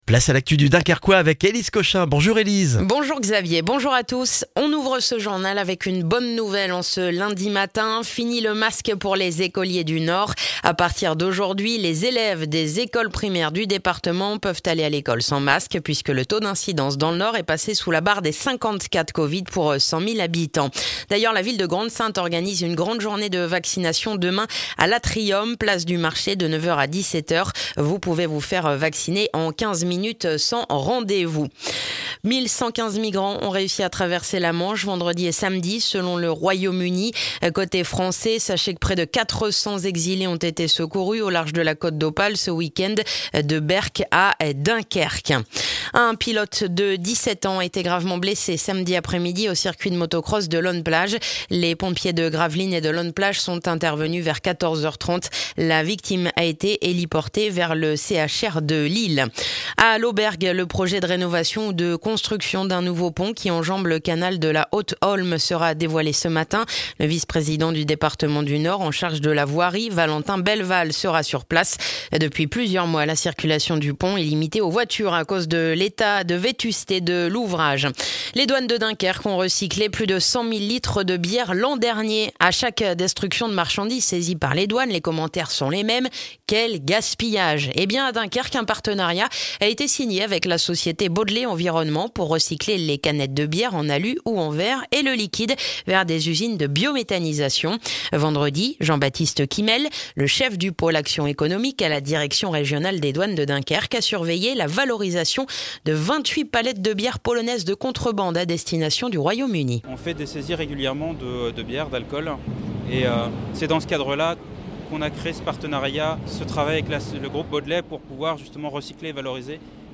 Le journal du lundi 11 octobre dans le dunkerquois